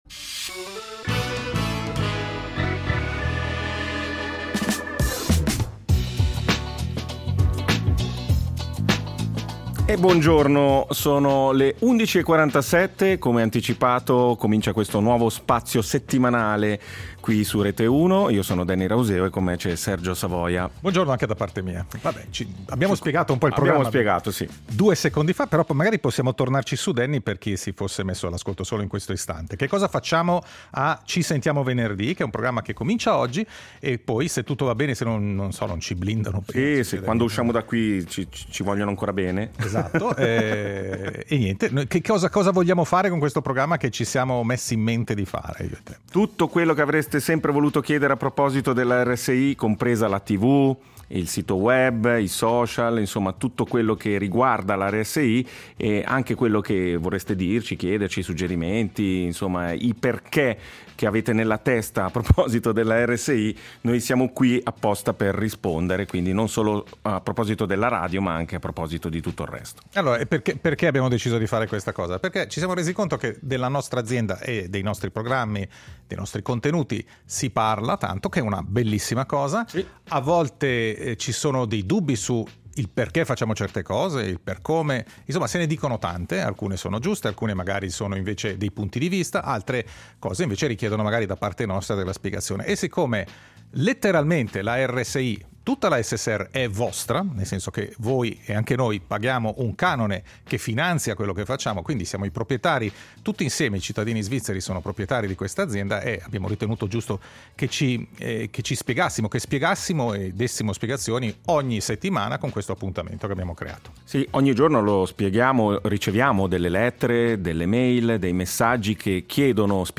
Spazio al confronto su Rete Uno
Insomma, “Ci sentiamo venerdì” è il tavolo radiofonico dove ci si parla e ci si ascolta.